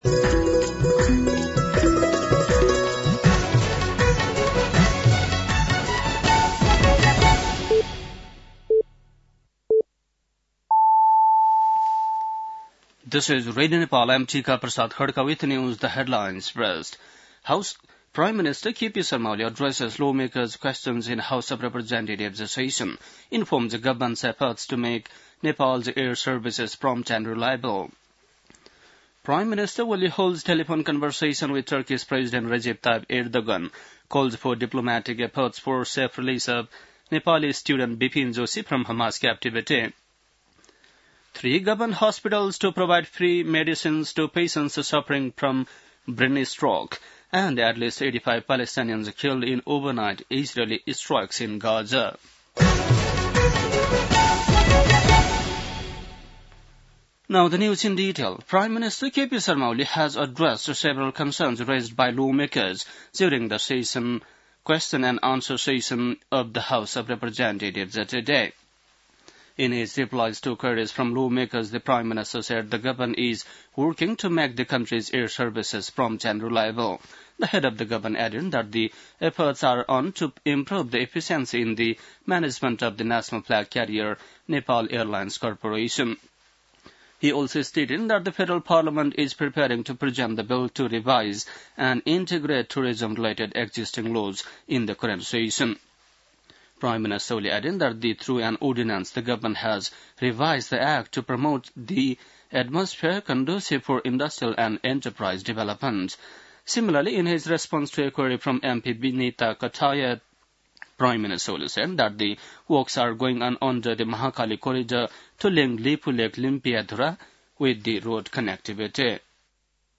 बेलुकी ८ बजेको अङ्ग्रेजी समाचार : ७ चैत , २०८१